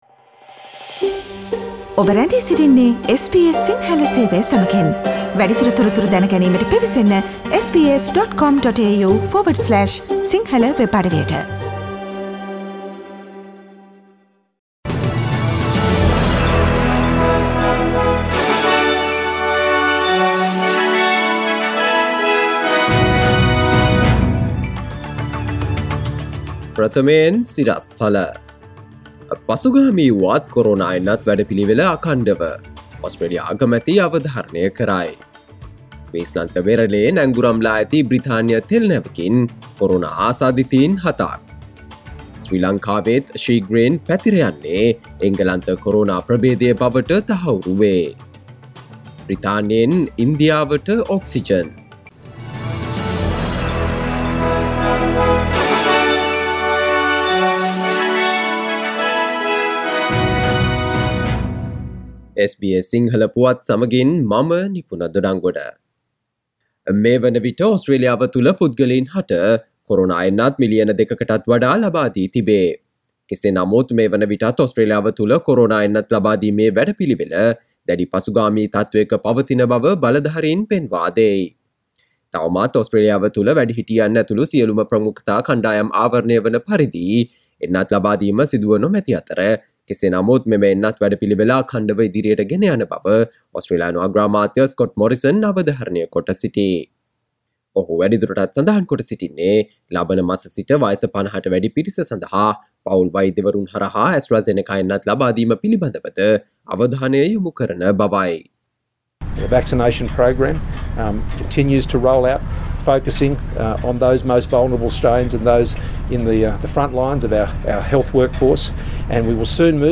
Here are the most prominent Australian, Sri Lankan, International, and Sports news highlights from SBS Sinhala radio daily news bulletin on Thursday 29 April 2021.